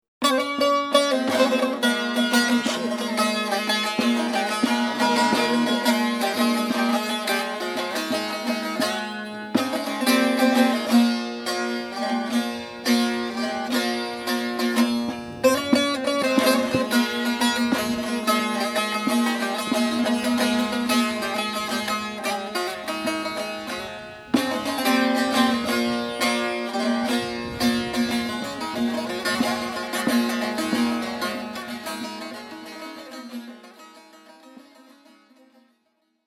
Genres: Turkish Traditional, Folk.